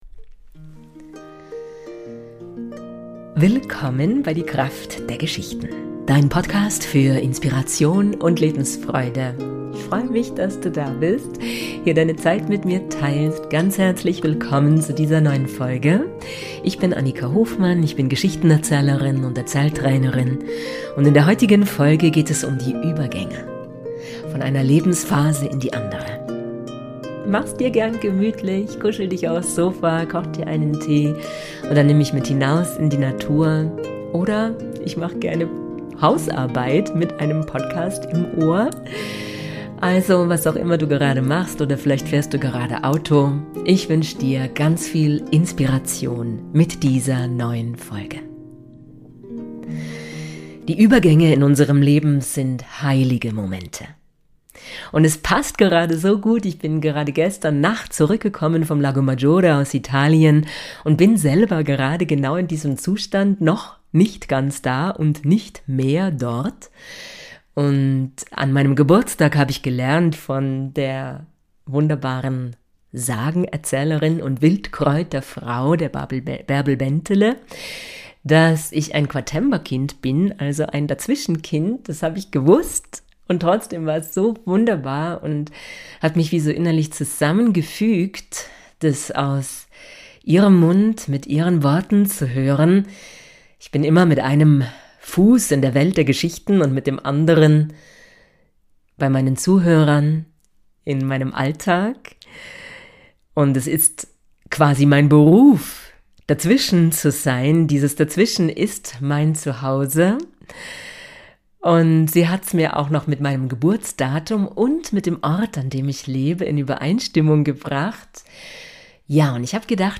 Ich habe eine ganz wunderbare Meditation für Dich.